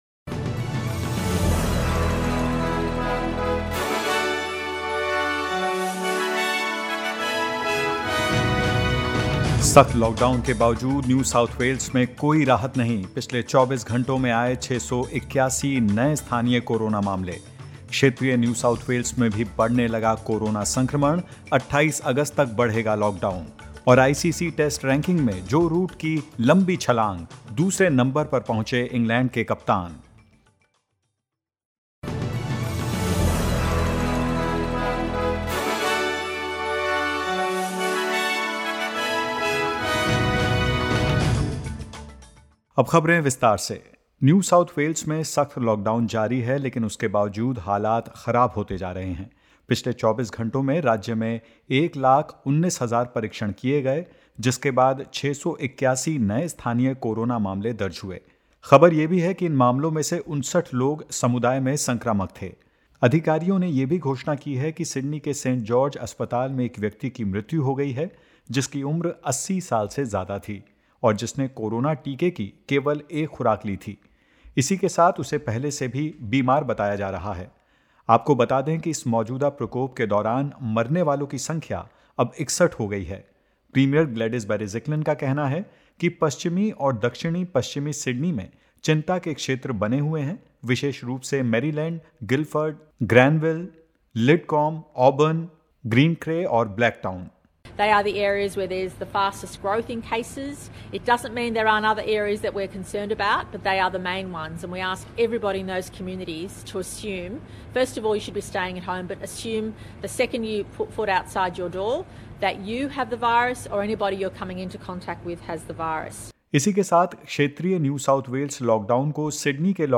In this latest SBS Hindi News bulletin of Australia and India: Lockdown affecting NSW's regional areas extended until 28 August; Victoria records surge in case numbers and marks 200th day of lockdown during pandemic and more.